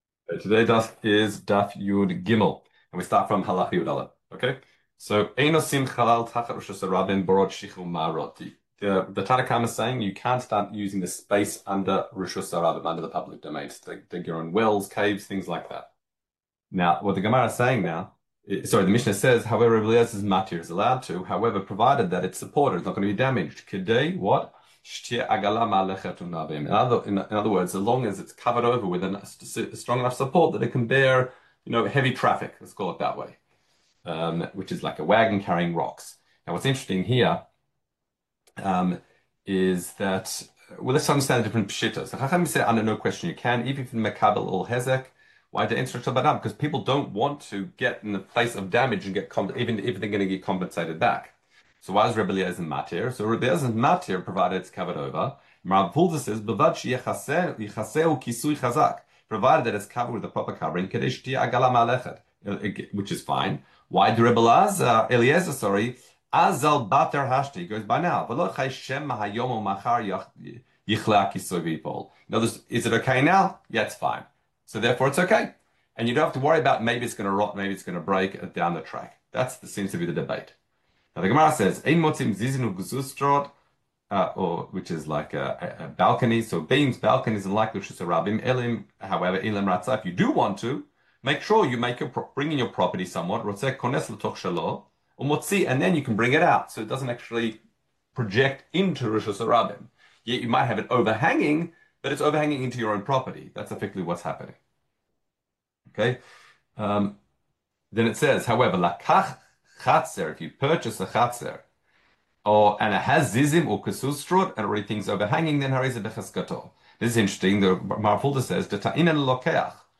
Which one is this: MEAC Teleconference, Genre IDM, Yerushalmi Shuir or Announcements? Yerushalmi Shuir